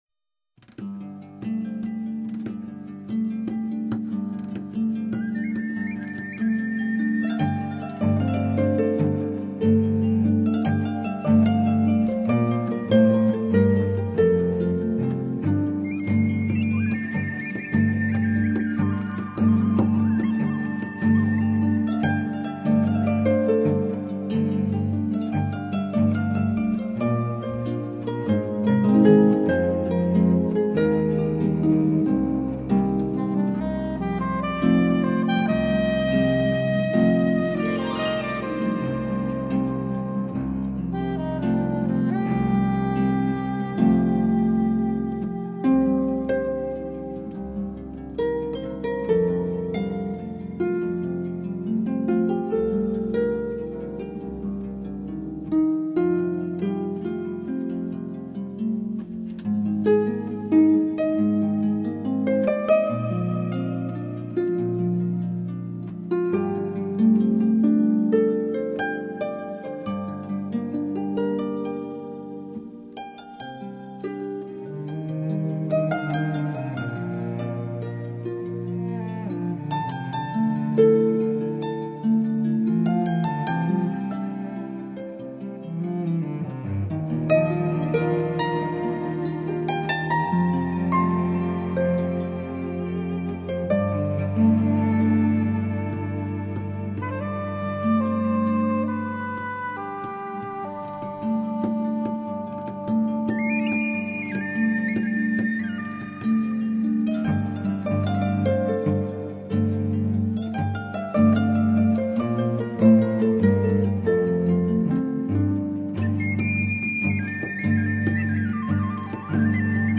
竖琴音乐近百年来,最彻底的超现代主义表现！